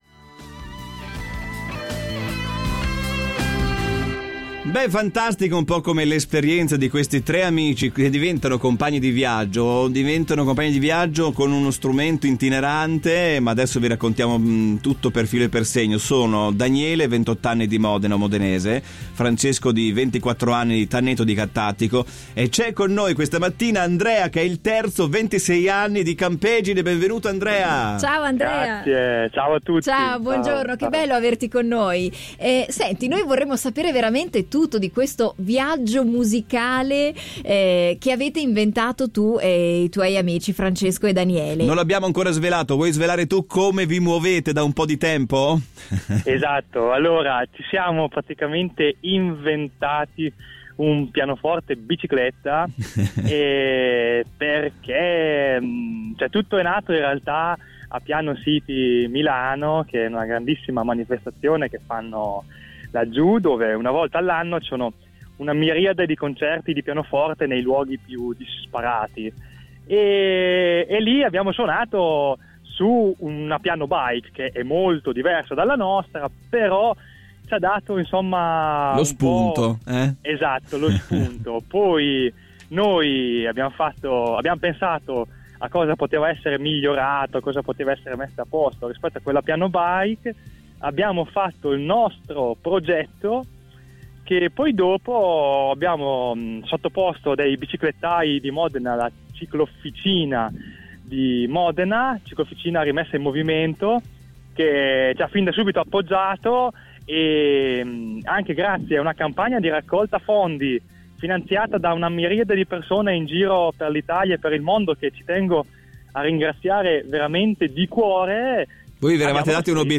Ecco il racconto di uno dei fautori